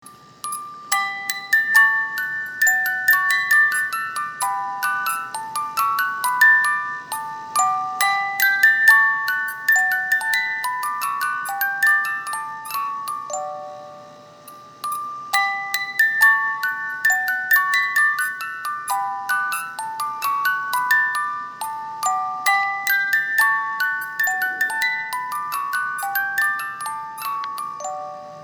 因為機械設計的限制，十八音梳機芯，只有十五秒左右的旋律，上發條後，這十五秒左右的旋律會重覆的撥放，直到發條鬆了為止！
機芯轉動時皆會有運轉聲、金屬磨擦聲(電動機芯也會有馬達運轉聲)，請知悉。